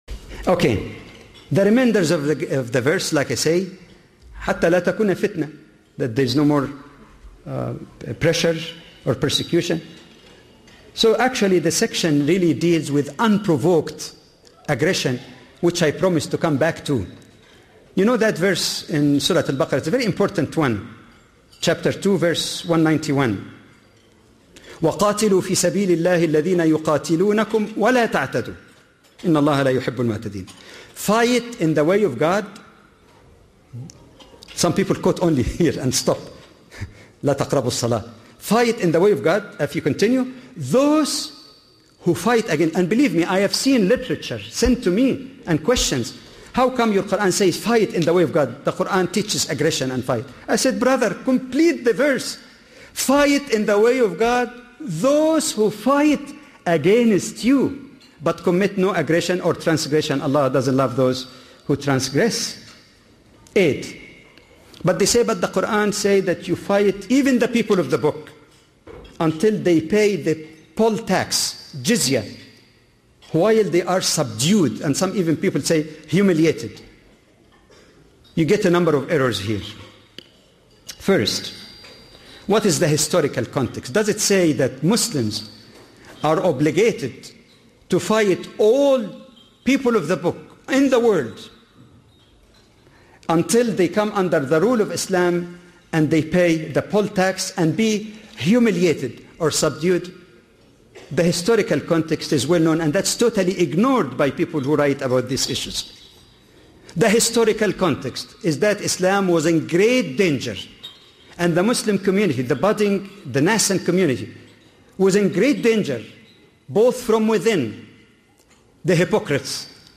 Muslim/Non-Muslim Relations: Commonly Misunderstood Qur'anic Texts - A lecture by Dr. Jamal Badawi.